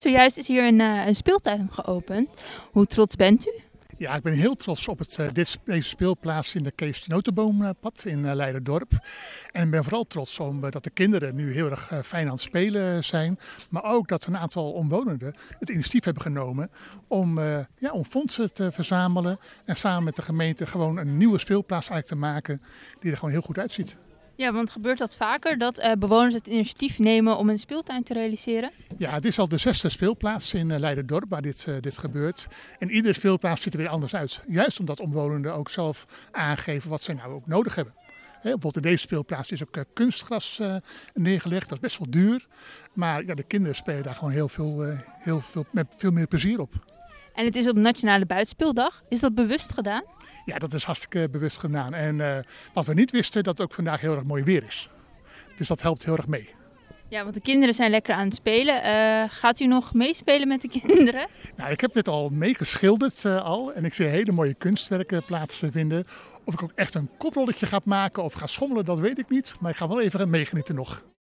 Wethouder Romeijn over de nieuwe speeltuin in Leiderdorp.